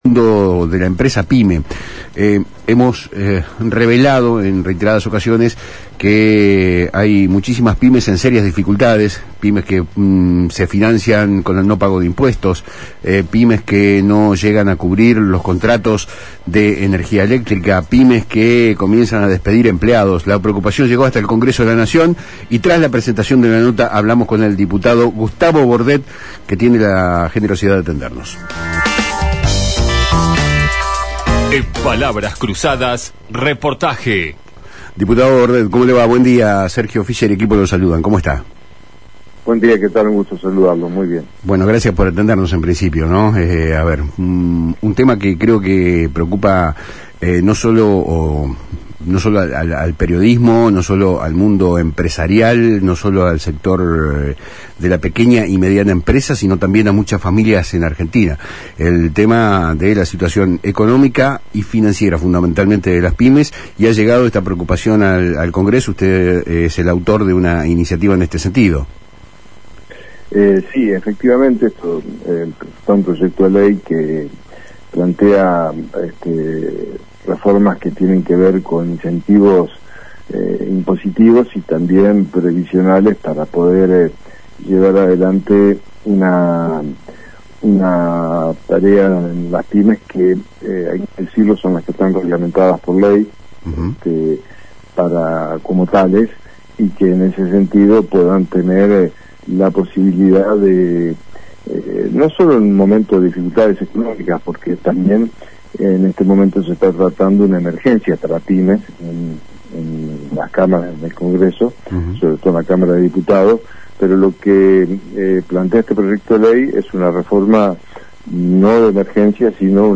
En una entrevista concedida a Palabras Cruzadas por FM Litoral, el legislador entrerriano desgranó los detalles de su proyecto de ley, que busca ir más allá de las medidas paliativas y sentar las bases para una recuperación sostenida del sector.